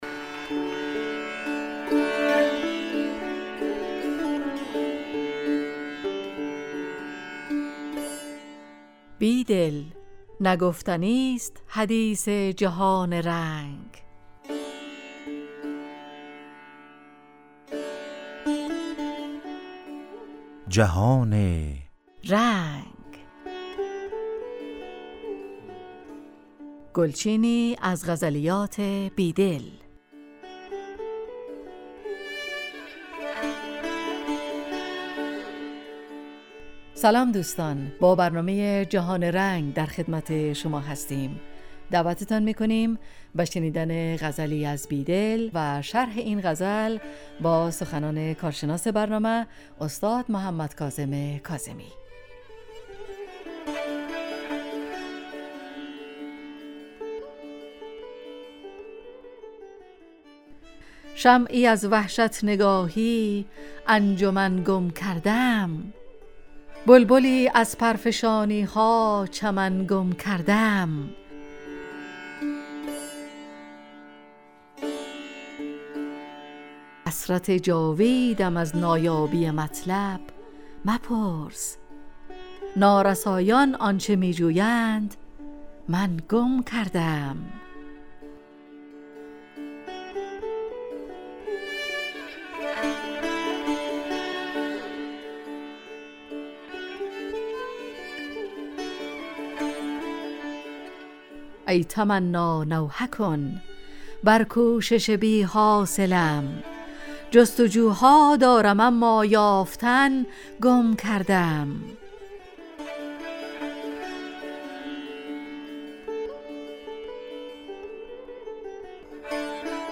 شمعی از وحشت نگاهی، انجمن گم کرده ام/ بلبلی از پرفشانی ها چمن گم کرده ام: غزلی از بیدل